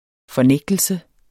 Udtale [ fʌˈnεgdəlsə ]